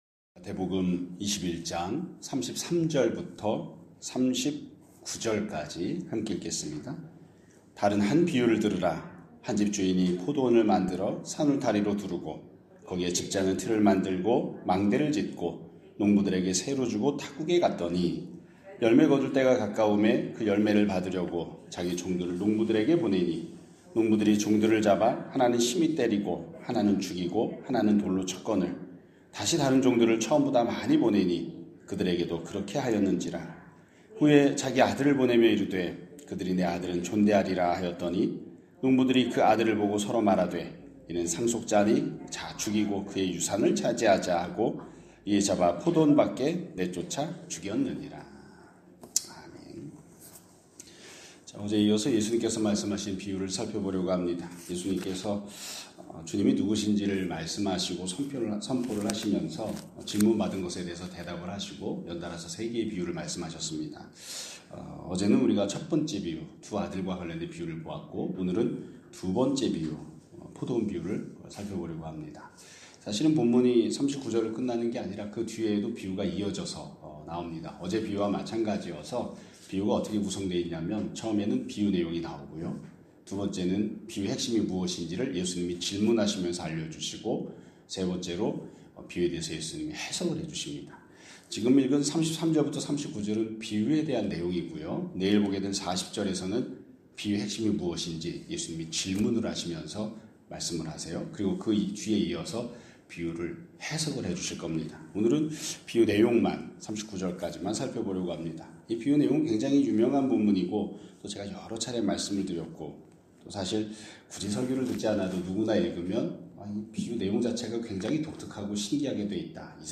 2026년 2월 3일 (화요일) <아침예배> 설교입니다.